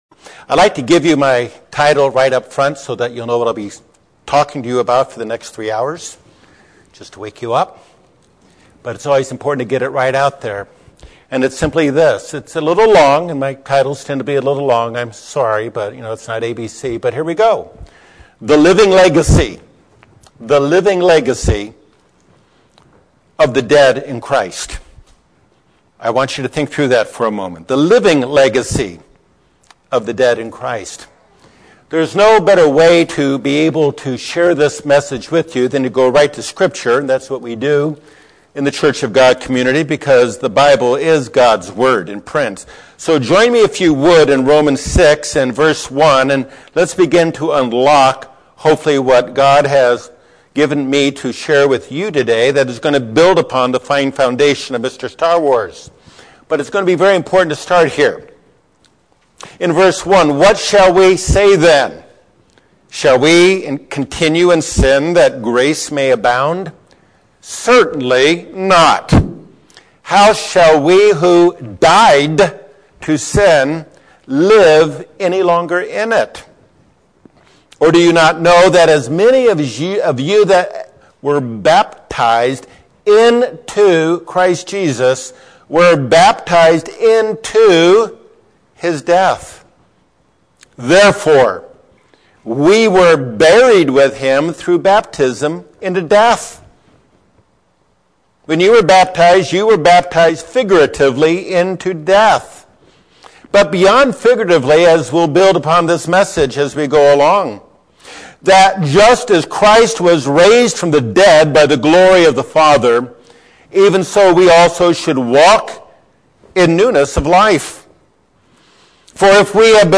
This message focuses on the epitaphs of three tombstones that live beyond the people buried below them.